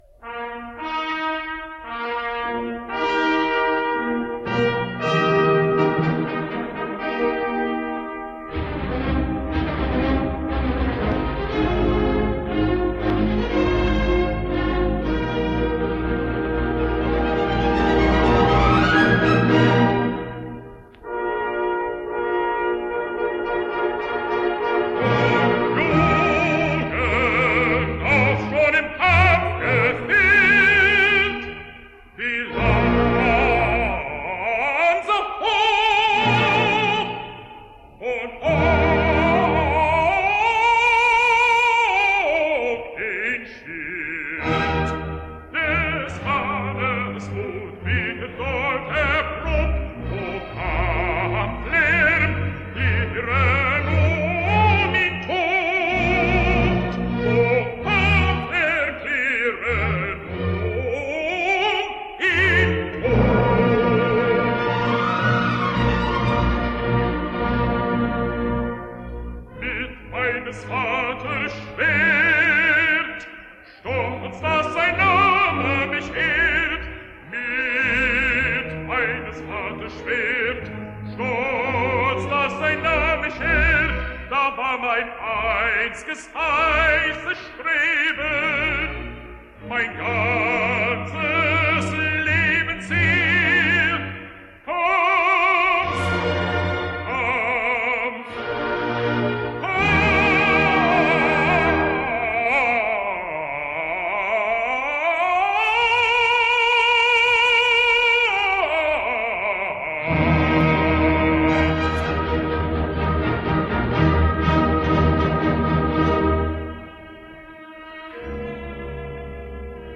American Tenor
Aria / Oberon  – Jess Thomas